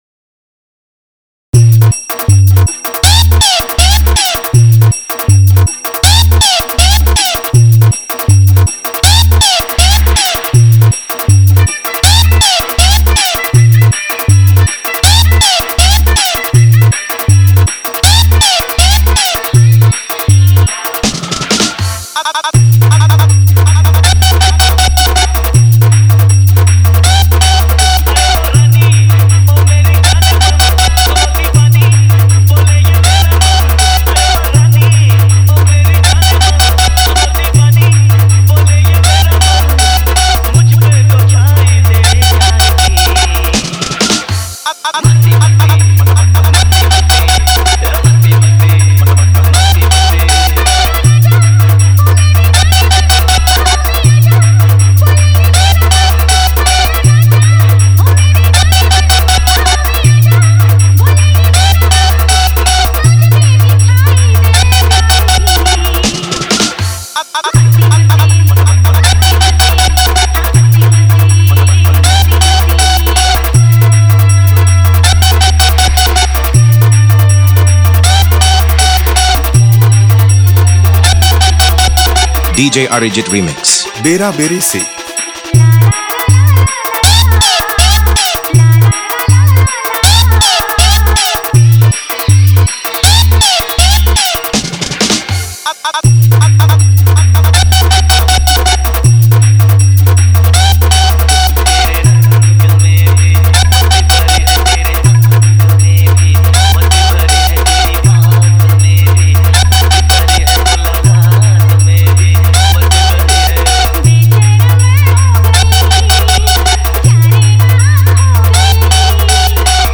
Horror Hummbing Bass Mix